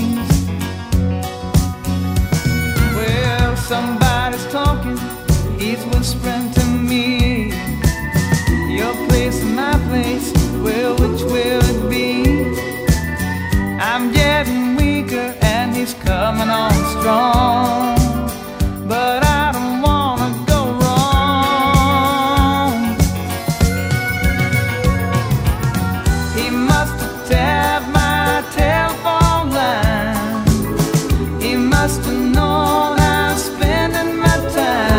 Жанр: Поп музыка / Рок / Кантри